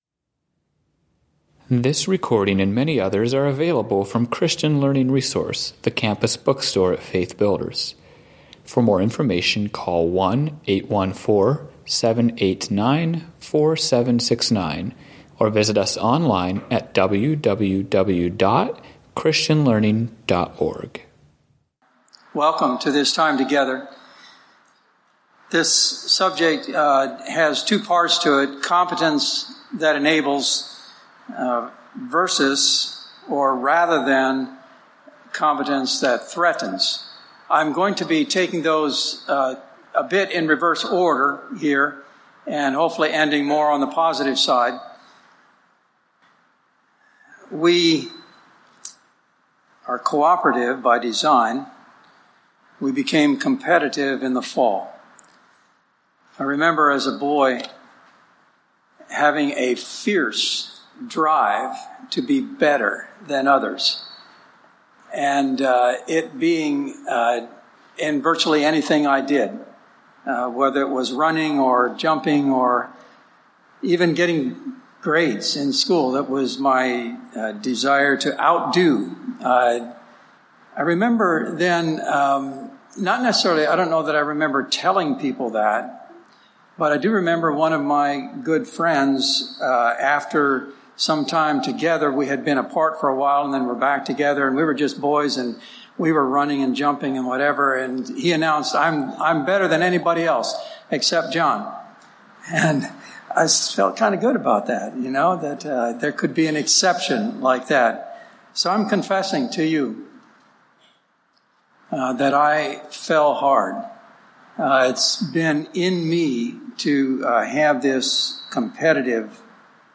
Home » Lectures » Competence that Enables Rather than Threatens or Competes